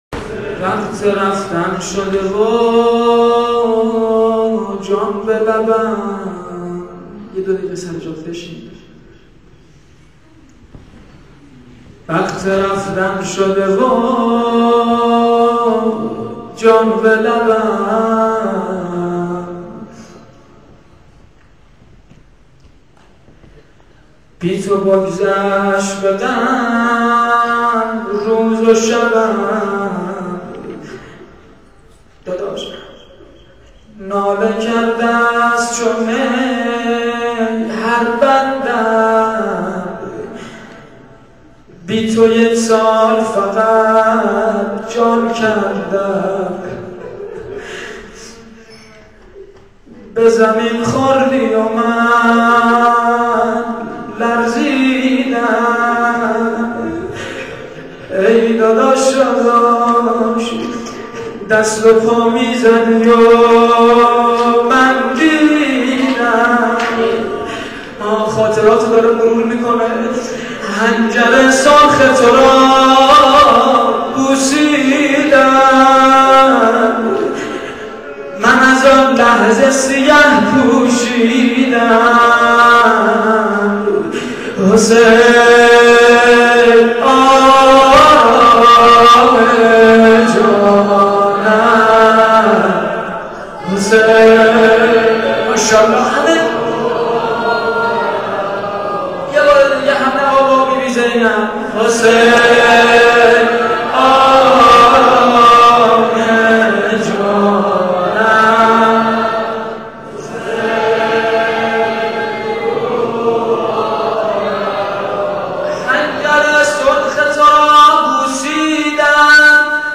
روضه.wma